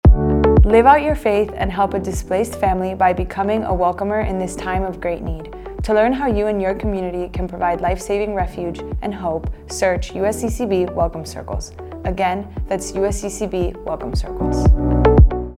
15 Second PSA
Welcome Circles 15 Female_1.mp3